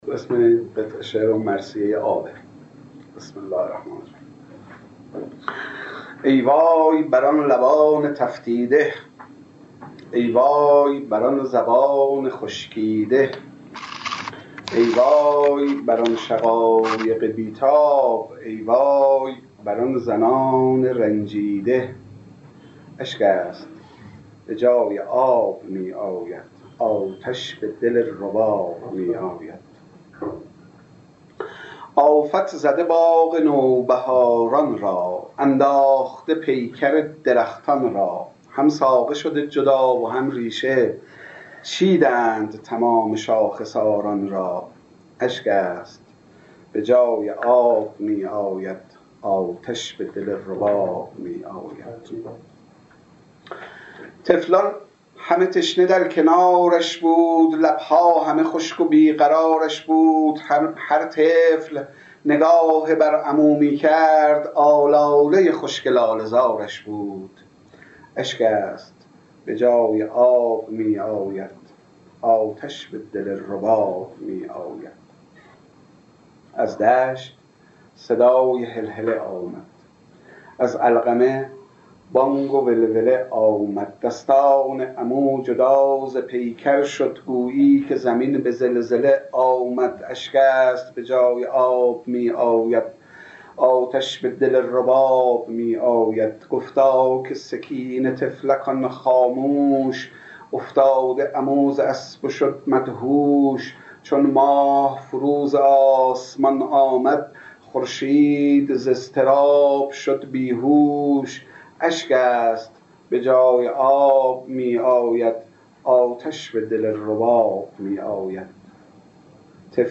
در پایان یکی از میزگردهای نقد عرفان‌ حلقه، به ذکر مصیبت حضرت رباب(س) و طفل شیرخواره‌اش با عنوان «مرثیه آب» پرداخت.